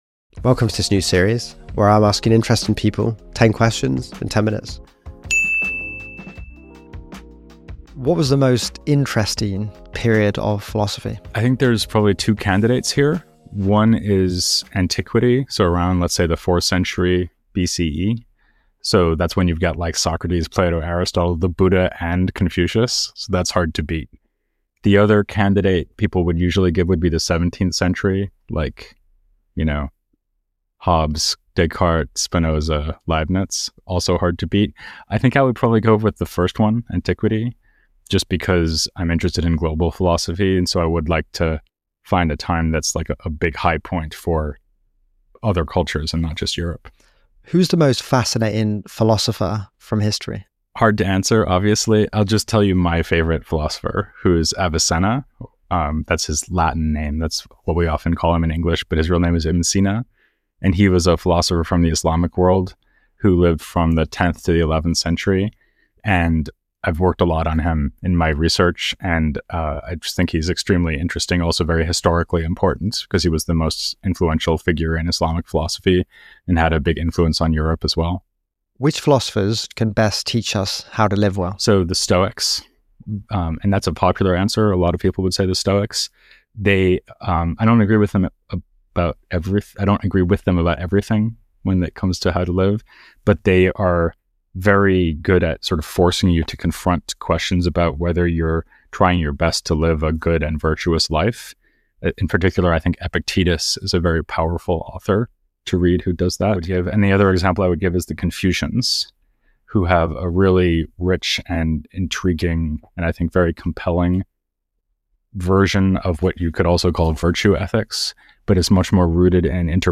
Welcome to the new quickfire series where I'm asking a wide range of people, from different careers, 10 Questions in 10 Minutes!